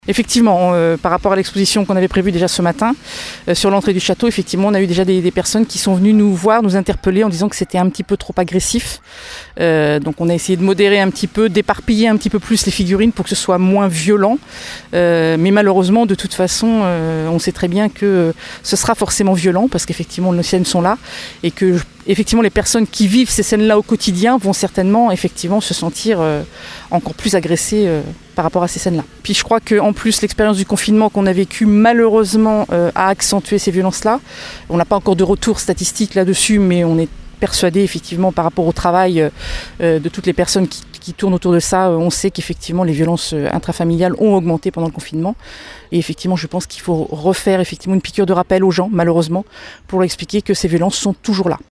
On écoute Pascale Gris, adjointe au maire en charge des affaires sociales :